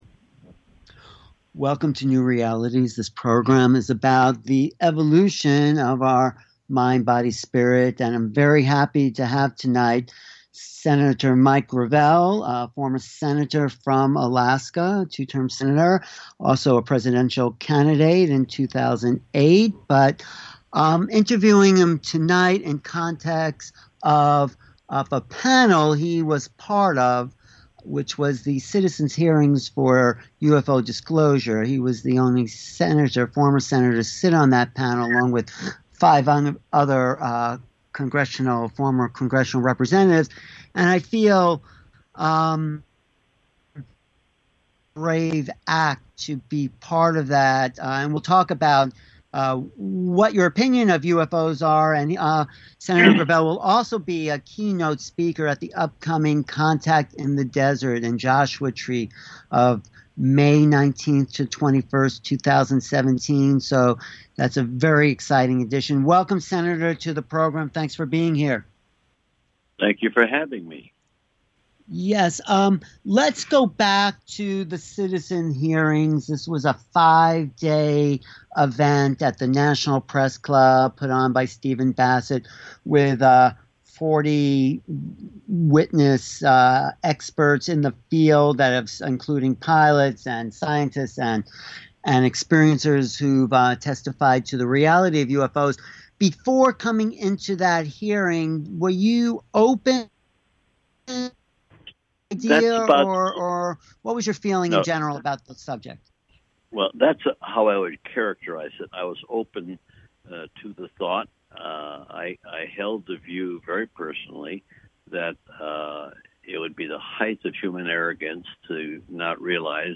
Talk Show Episode, Audio Podcast, New Realities and with former Senator Mike Gravel on , show guests , about Mike Gravel, categorized as News,Politics & Government
with former Senator Mike Gravel